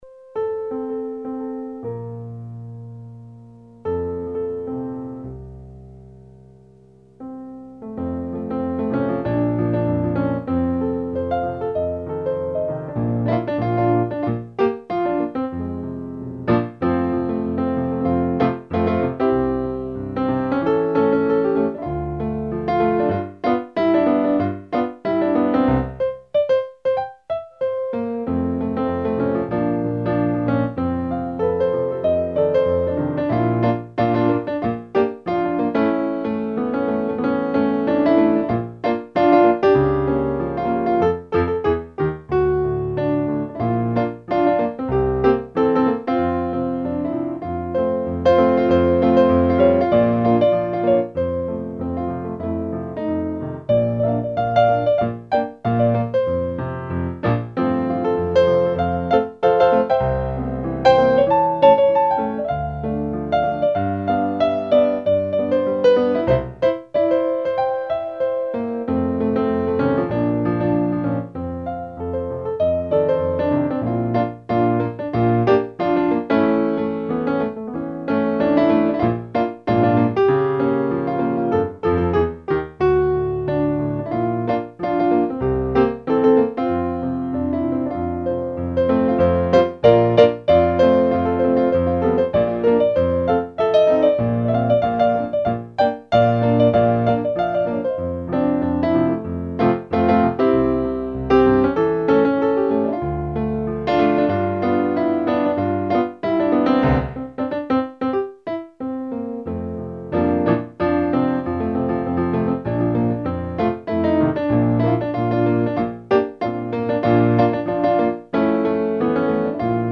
Piano live für Ihre Party